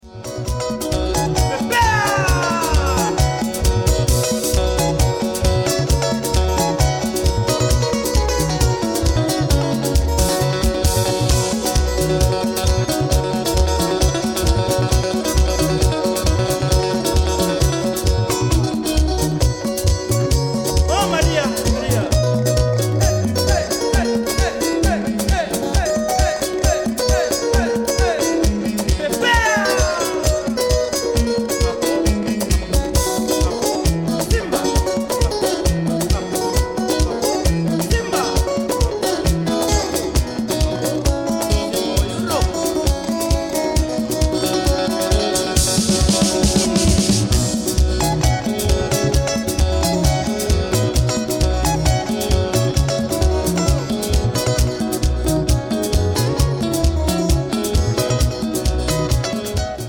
recorded this album in Holland in 1991
extra guitar and percussion being added later